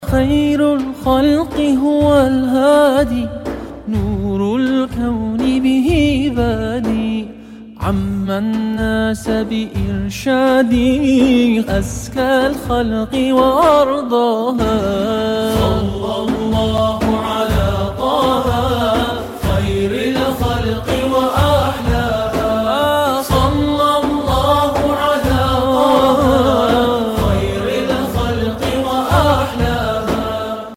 زنگ موبایل با کلام(عربی) ملایم و زیبای